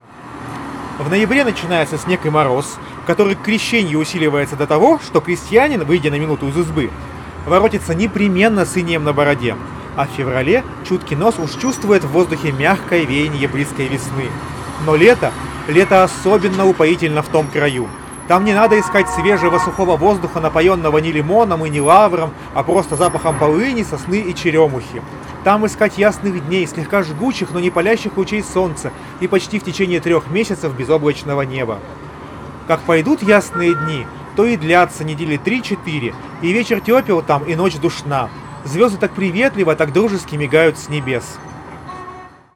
Чтобы оценить ее эффективность, мы вновь запишем два тестовых фрагмента, но на этот раз добавим фоновый шум: включим в расположенной недалеко от места записи акустике звук оживленной улицы и посмотрим, как это отразится на разборчивости речи.
Запись на профессиональный рекордер (в шумной обстановке)